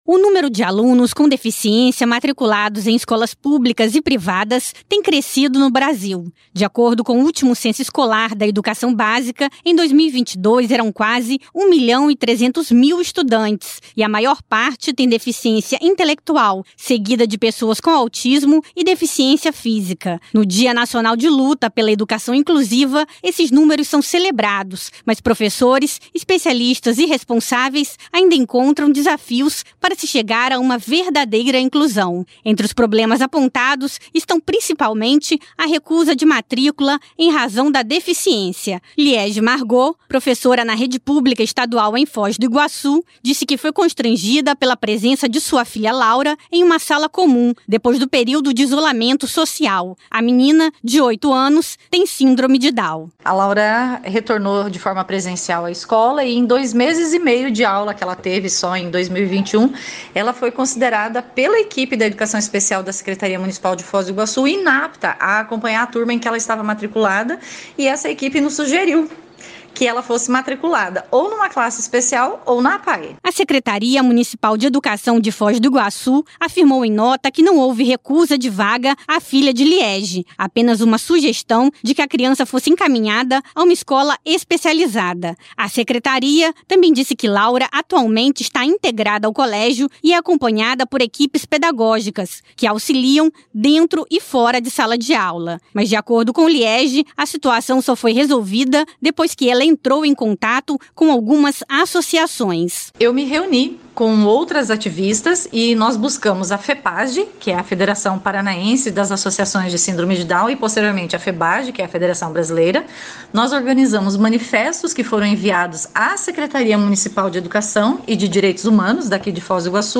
Radioagência Nacional traz duas reportagens especiais sobre os desafios da educação inclusiva no Brasil.